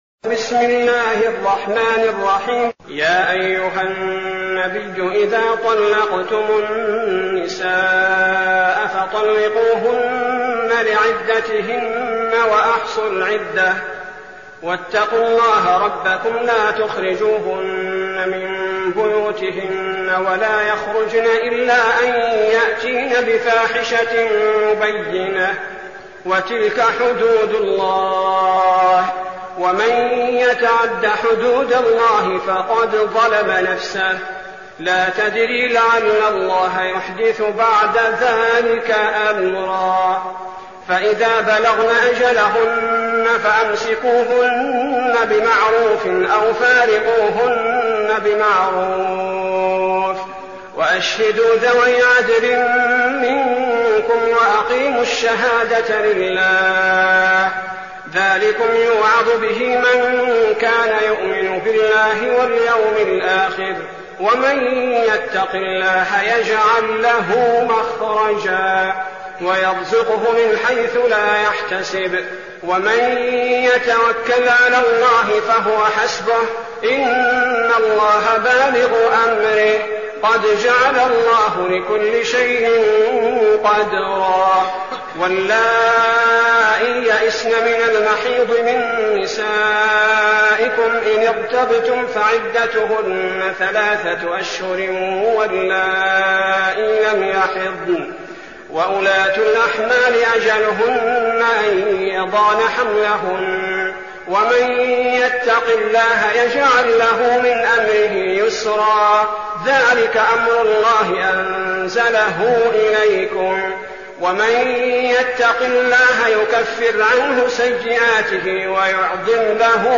المكان: المسجد النبوي الشيخ: فضيلة الشيخ عبدالباري الثبيتي فضيلة الشيخ عبدالباري الثبيتي الطلاق